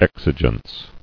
[ex·i·gence]